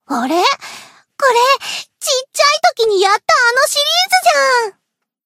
BA_V_Momoi_Cafe_Monolog_2.ogg